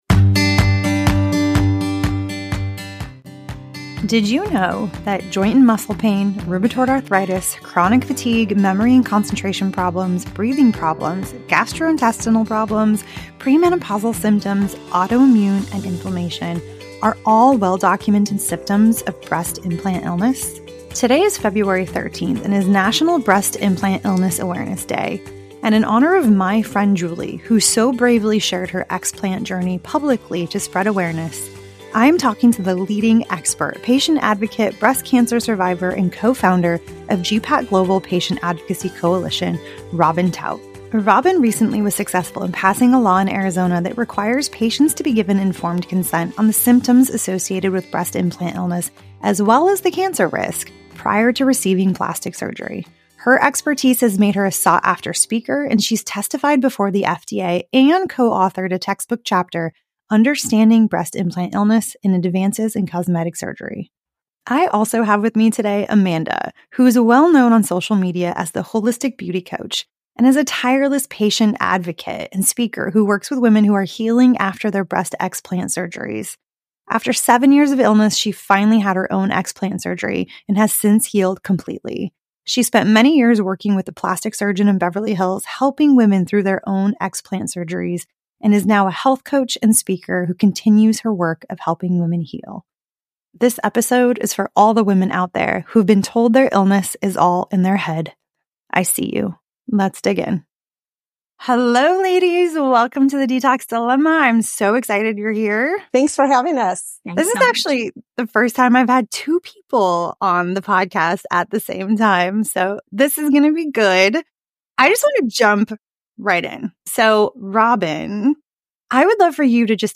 This is a conversation about transparency and patient autonomy in medical decisions.